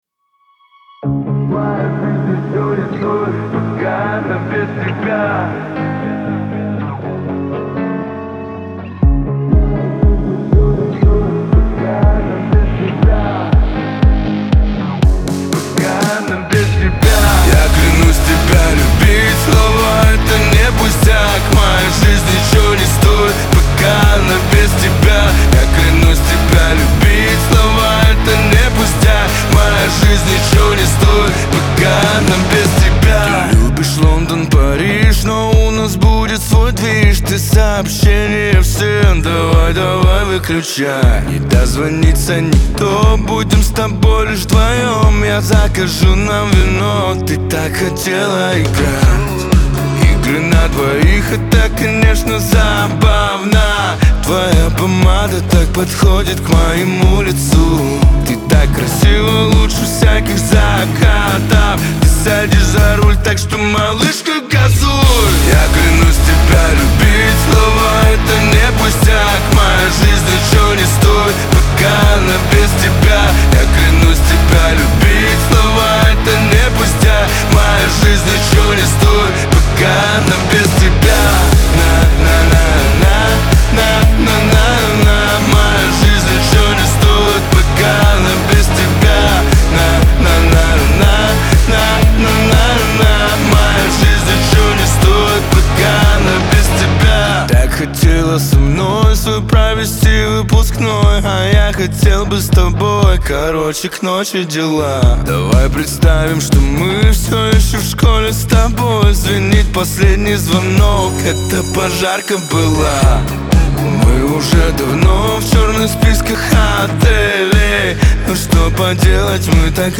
эстрада , ХАУС-РЭП